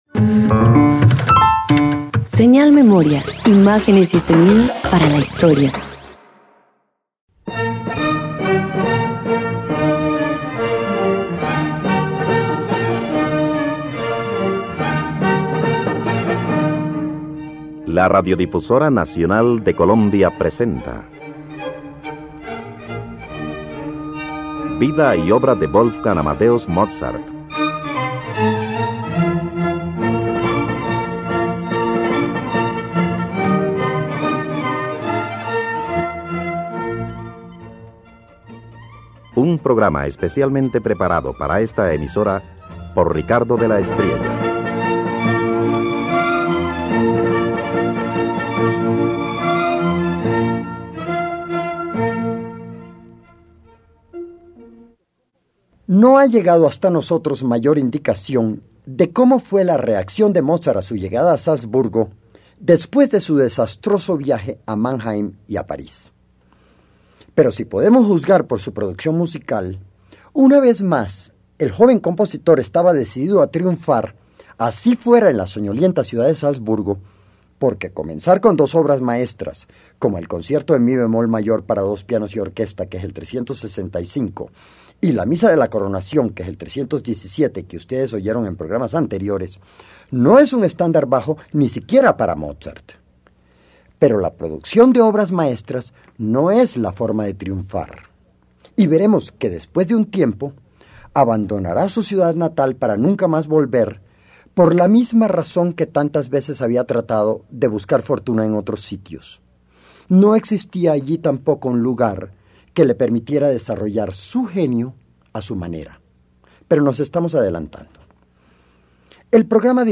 Mozart reafirma su impulso creativo con dos obras instrumentales: la sonata en si bemol mayor K378 y nuevas piezas marcadas por el eco de París. Música brillante que refleja su búsqueda de futuro más allá de la ciudad que lo vio nacer.